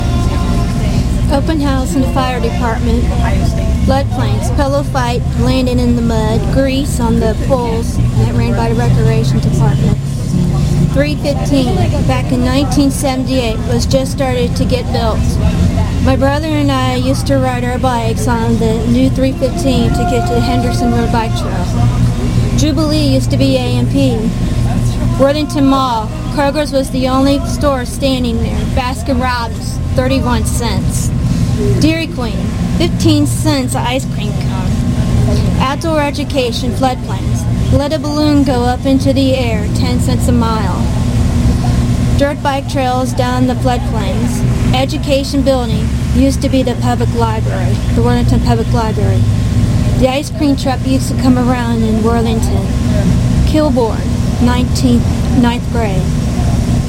with genre interview.